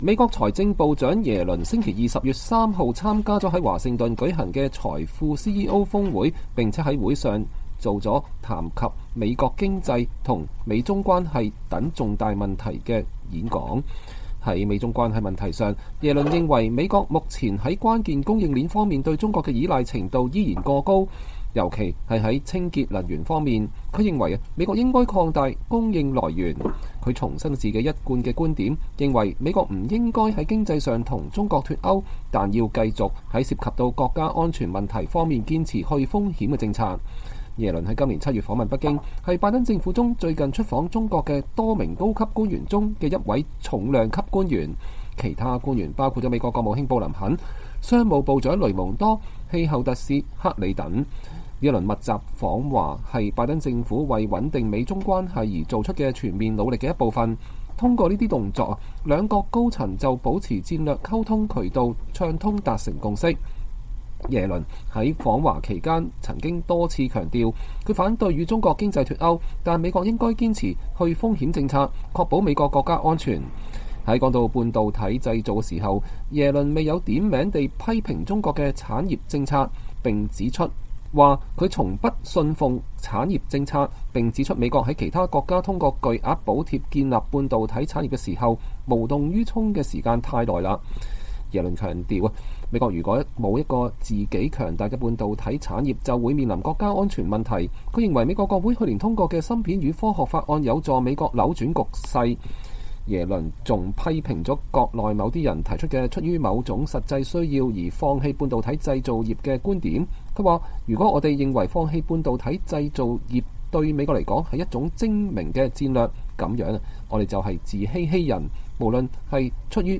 美國財長珍妮特耶倫週二（10月3日）參加了在華盛頓舉行的財富CEO峰會，並在會上作了談及美國經濟和美中關係關係等重大問題的講話。